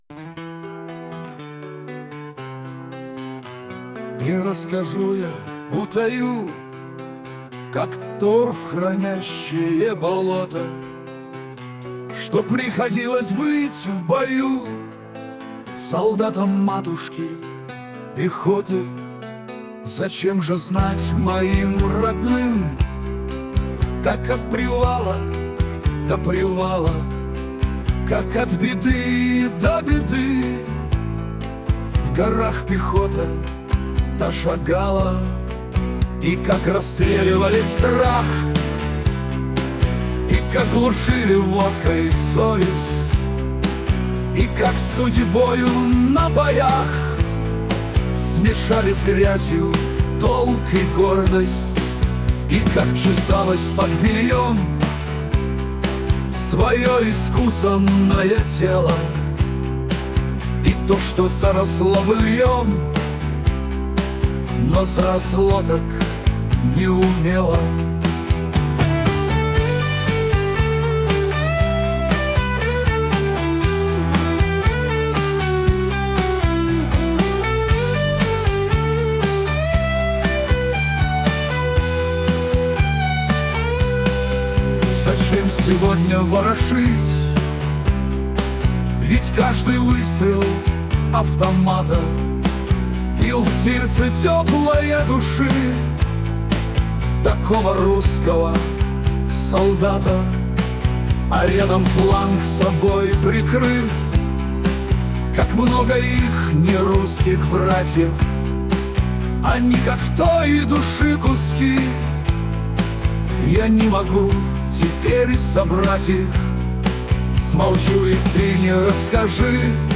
rasskazhi.mp3 (5626k) Попытка песни ИИ